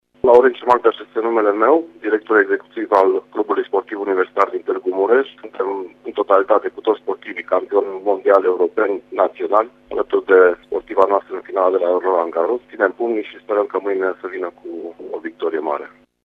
O dovedesc mesajele oamenilor de sport din județele Mureș și Harghita: